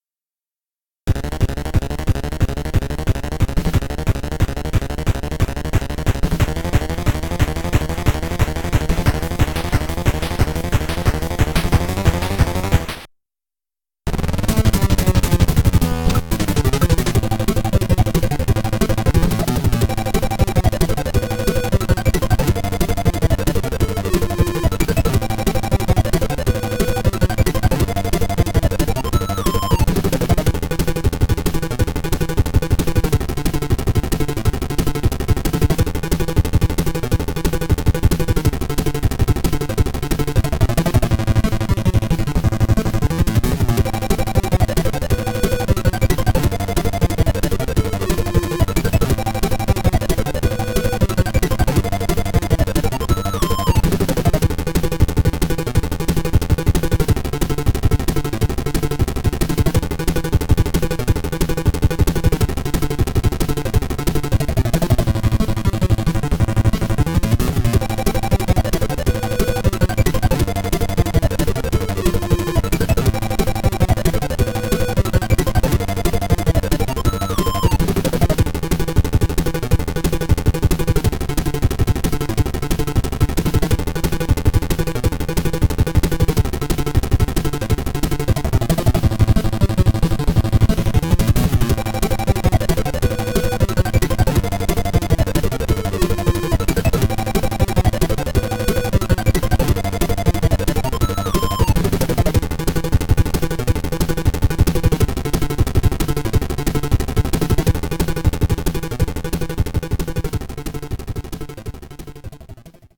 BPM174-180
Audio QualityPerfect (High Quality)
.ogg ripped directly from game (via the FCEUX emulator).